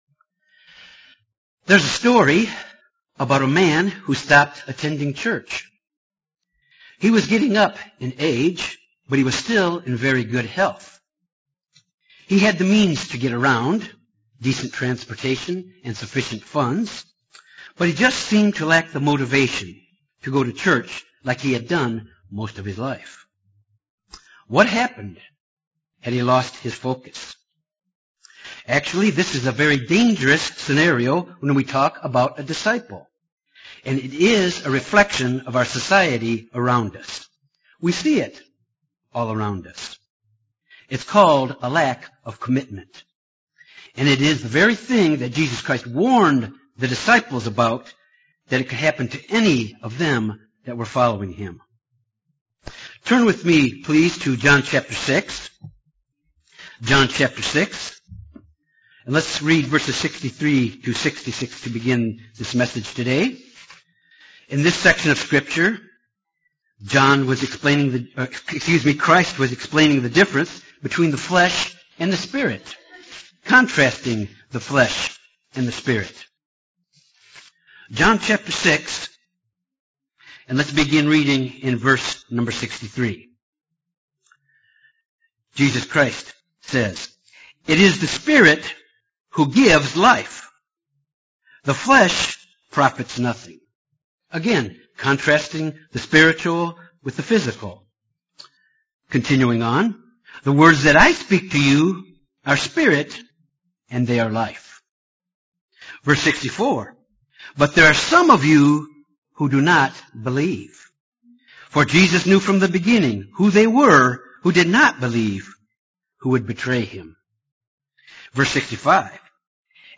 Given in Jonesboro, AR Little Rock, AR